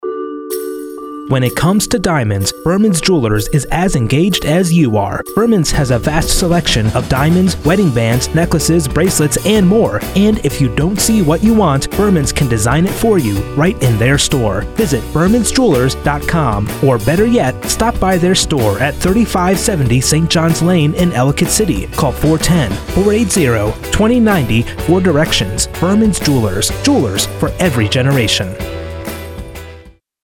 Jewelry store radio advertisment